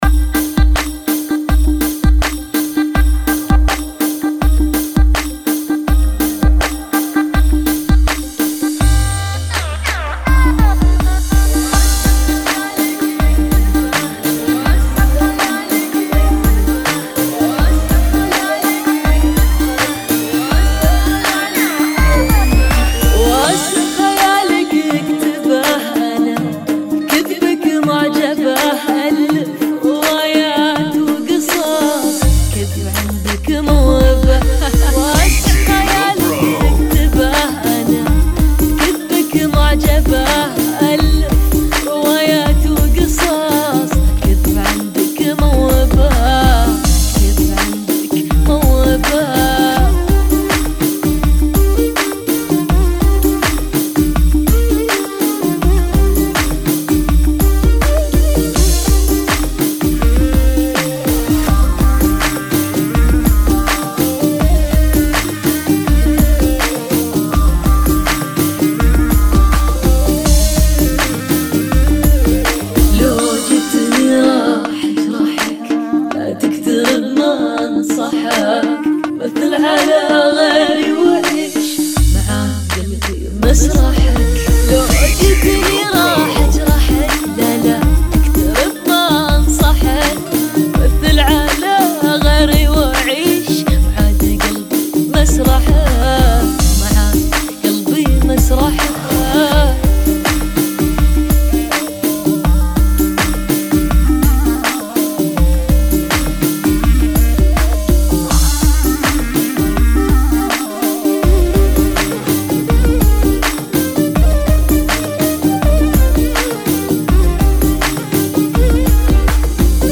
82 Bpm ] - Funky